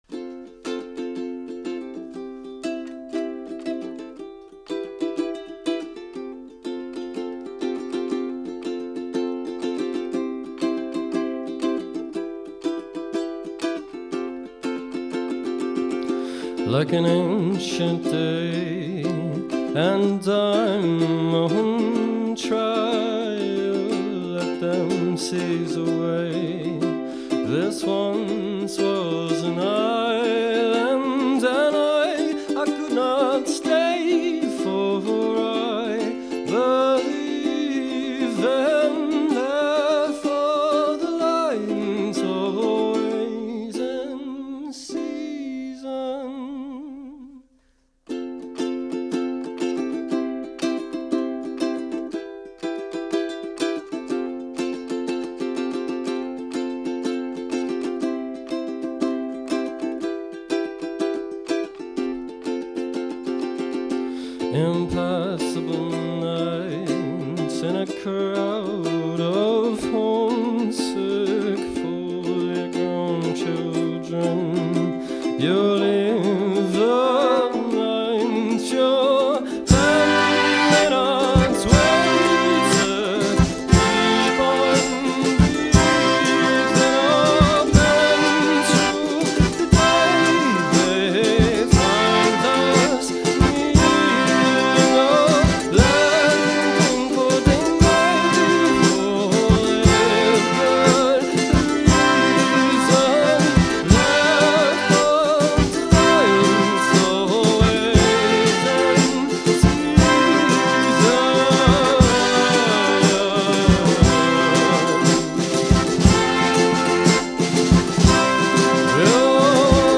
Recorded At Engine Studios